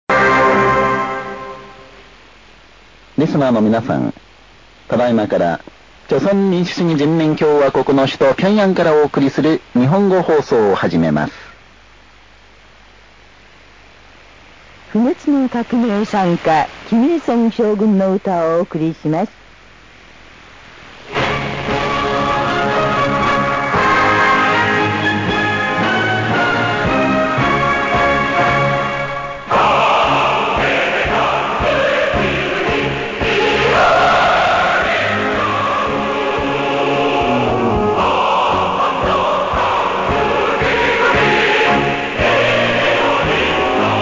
Receive mode : AM
Receiving signal samples of superheterodyne short wave radio kit
Received location : Cofu-city Tokyo, Japan
Antenna:10feet wire antenna